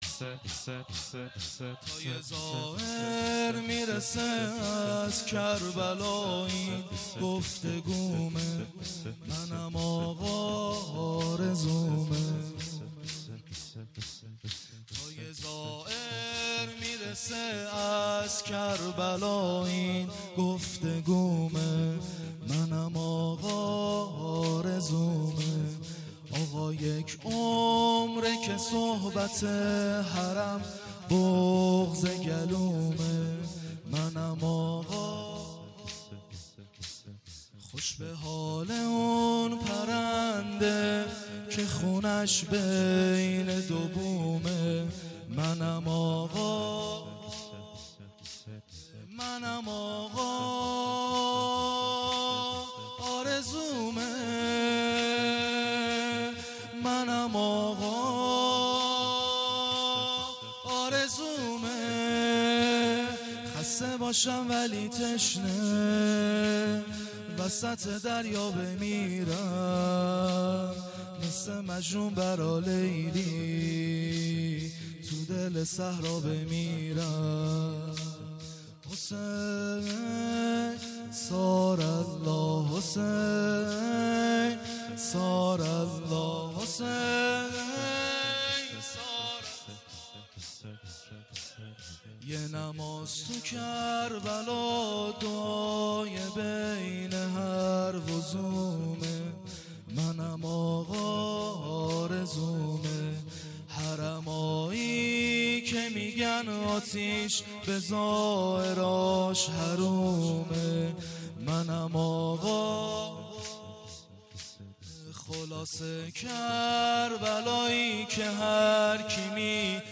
احساسی و زیبا(فوق العاده)